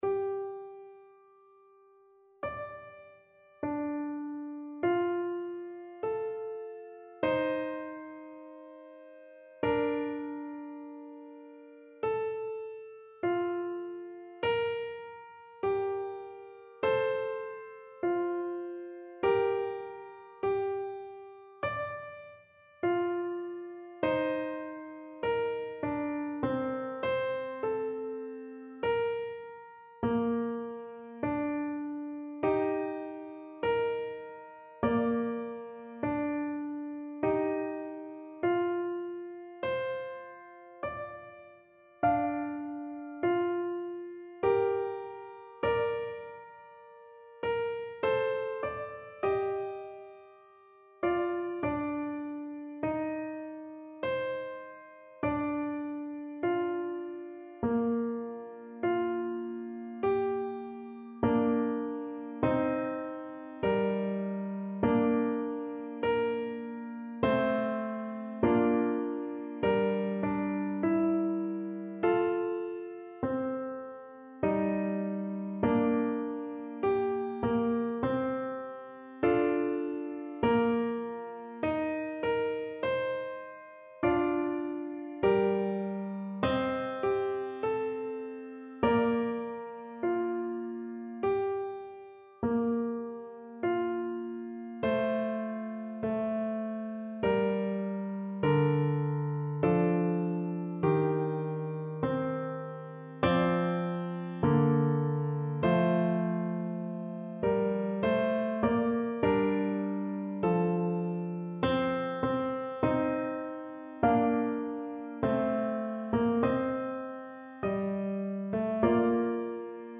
Classical Byrd, William Mass in Four Parts - Agnus Dei Piano version
No parts available for this pieces as it is for solo piano.
Bb major (Sounding Pitch) (View more Bb major Music for Piano )
4/4 (View more 4/4 Music)
Piano  (View more Intermediate Piano Music)
Classical (View more Classical Piano Music)